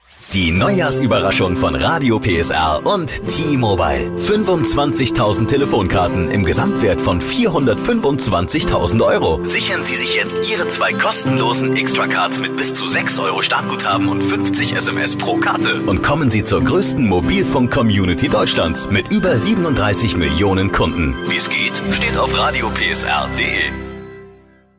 Trailer bei Radio PSR:
xtra-psr-trailer.mp3